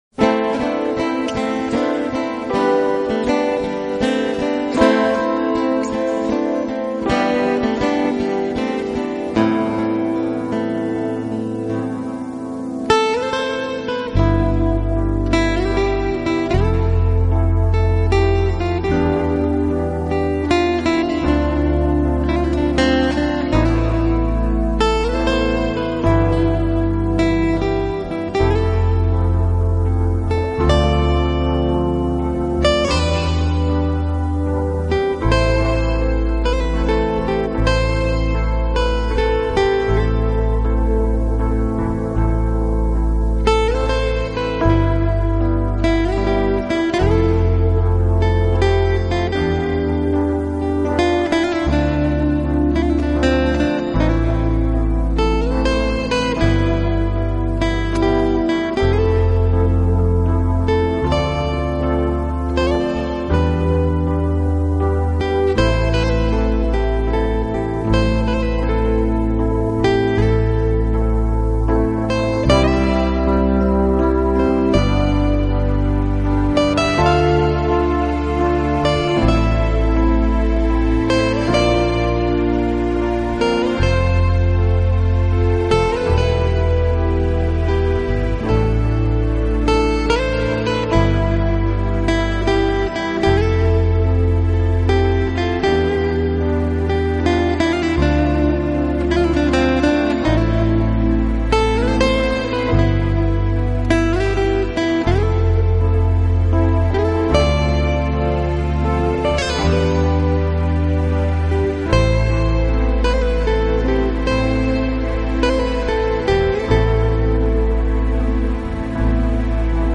【浪漫吉他】
专辑音色清脆动人且温馨旖丽，不禁展示了精彩绝伦的空间感，而且带出吉他音箱共鸣声的
浪漫吉他曲，经典西洋乐，音符似跳动的精灵，释放沉睡已久的浪漫情怀，用吉他的清脆表
现音乐的干净、深度和静谧美丽得让人心碎的旋律。